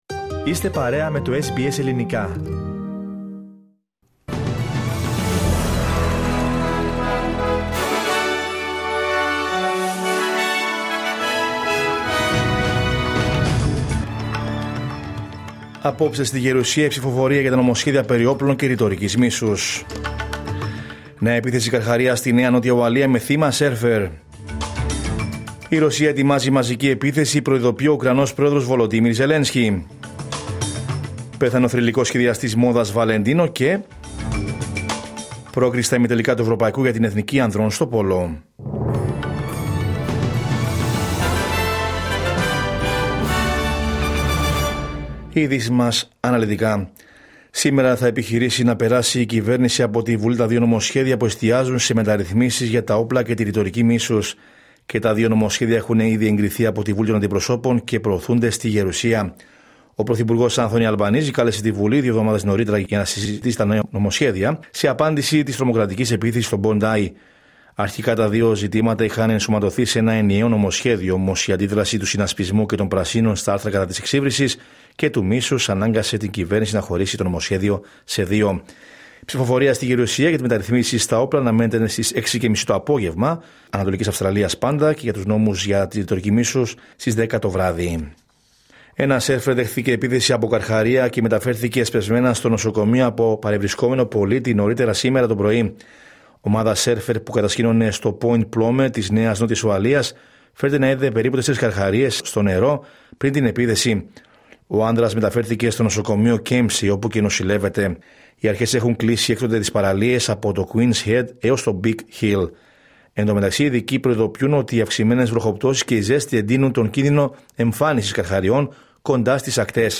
Ειδήσεις: Τρίτη 20 Ιανουαρίου 2026